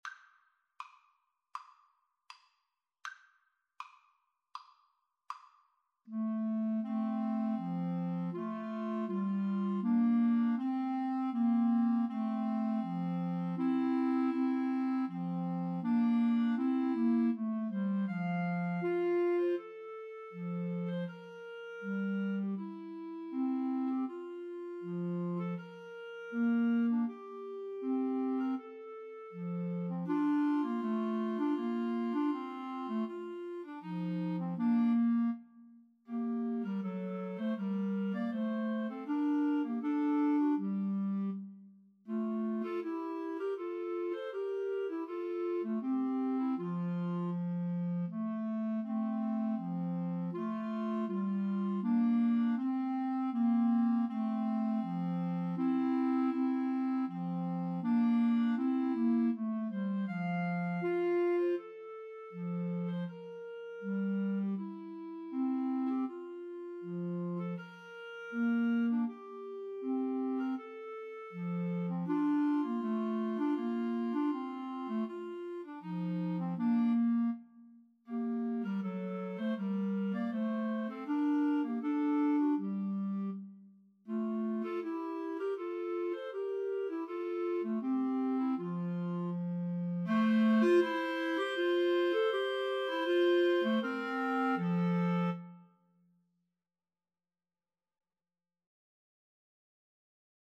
= 80 Andante
Classical (View more Classical Clarinet Trio Music)